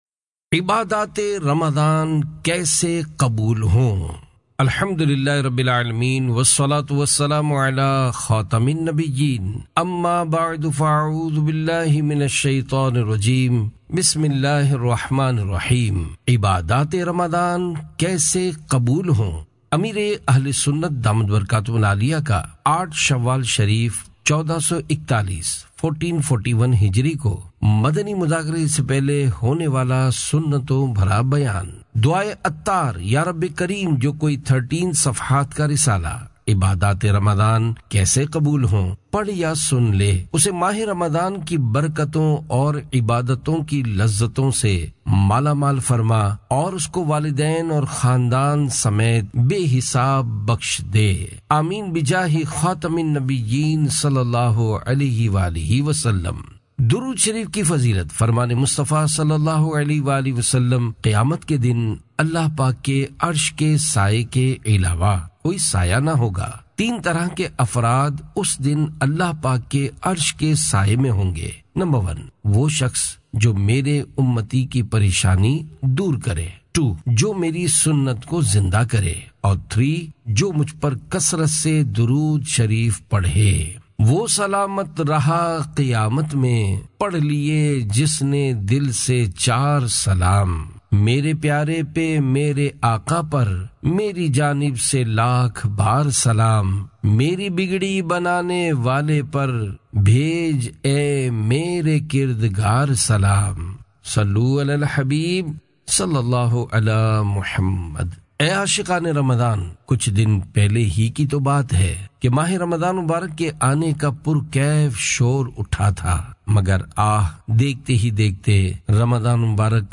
Audiobok - Ibadaat e Ramazan Kaese Qabool Ho?